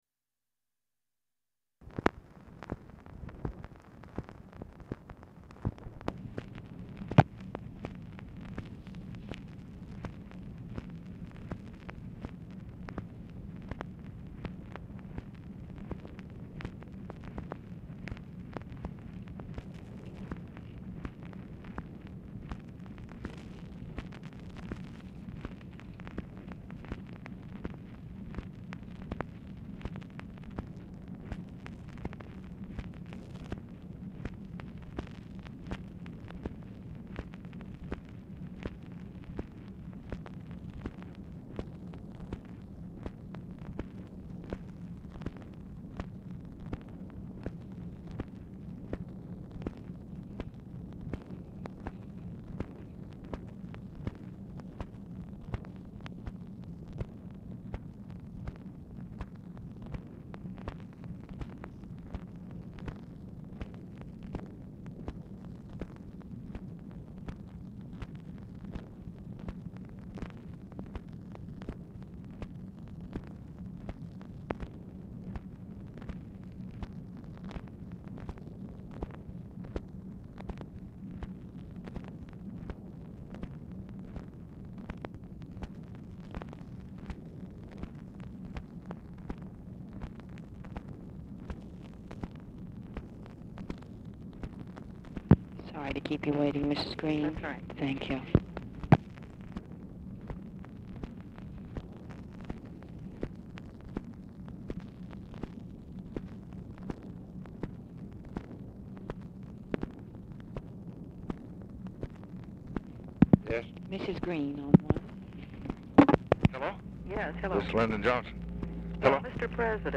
Telephone conversation # 11622, sound recording, LBJ and EDITH GREEN, 3/8/1967, 11:38AM | Discover LBJ
Format Dictation belt
Location Of Speaker 1 Oval Office or unknown location